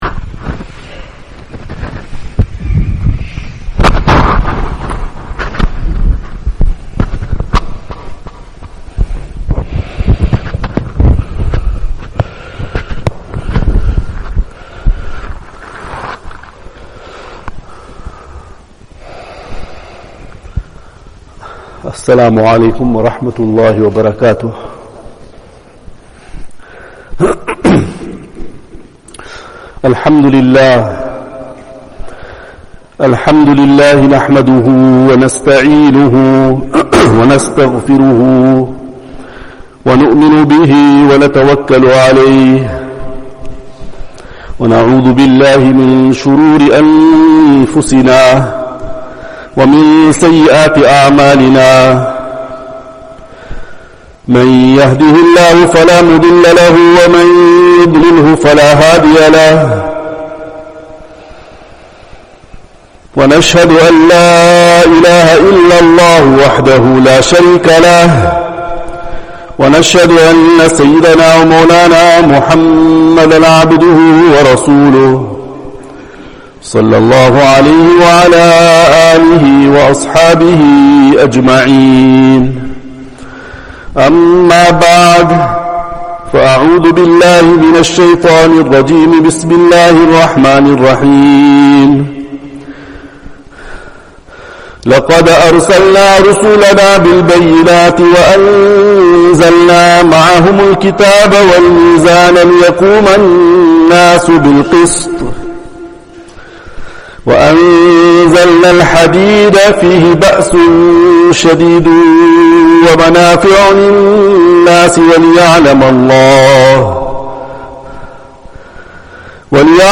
Easily listen to Islamic Lecture Collection